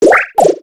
Cri de Doudouvet dans Pokémon X et Y.